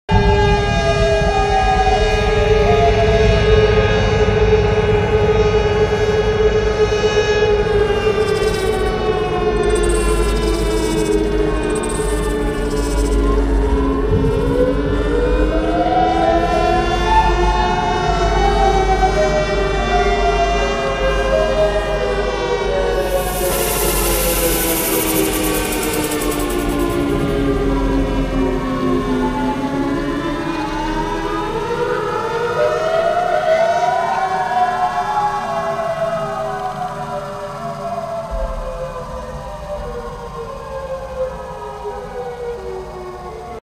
Siren Interlude.wav